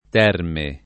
terme [
t$rme] s. f. pl.